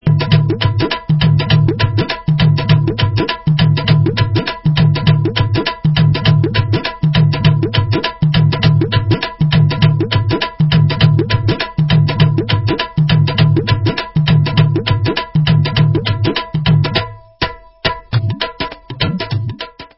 Dholak & Bugchu